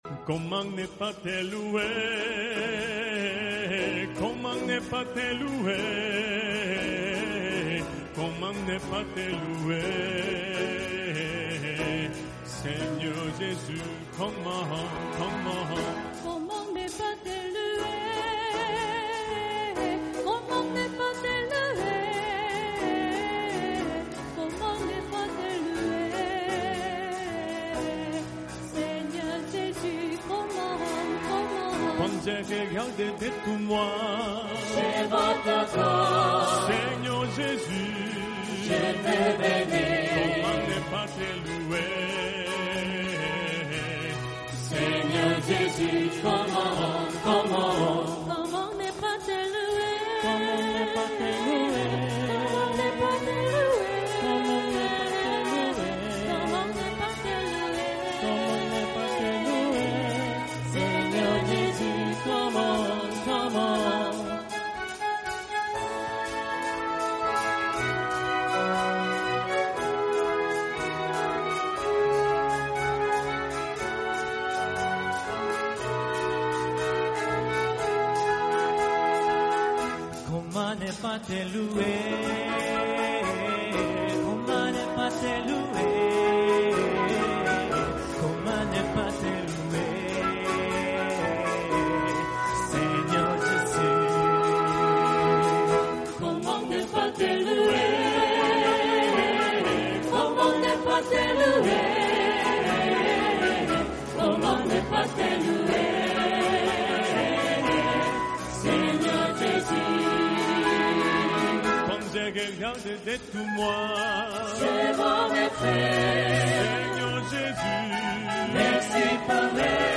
A beautiful moment of worship